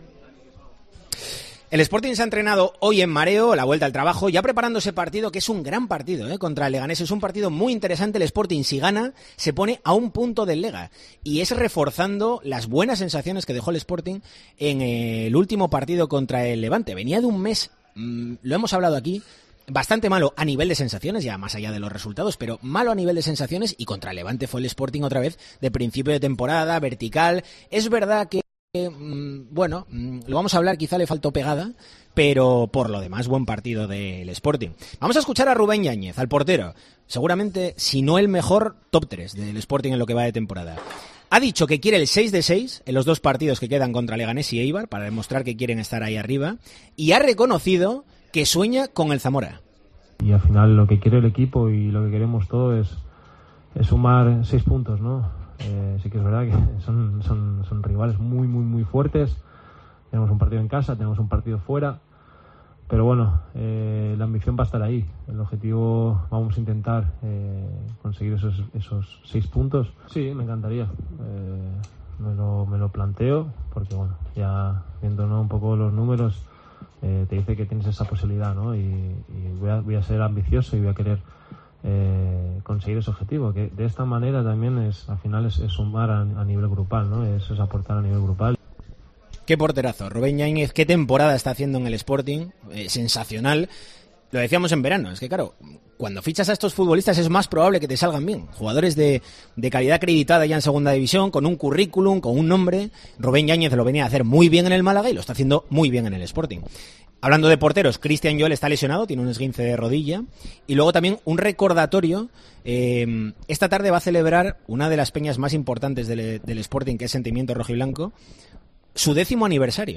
En el capítulo de este martes de 'El Tertulión del Sporting' en Deportes COPE Asturias , debatimos acerca de la polémica arbitral que ha dejado como resultado la expulsión de Djuka frente al Levante , la mejora en las sensaciones ante el Levante o la falta de gol , con el fichaje de un delantero en el horizonte del mercado de fichajes invernal.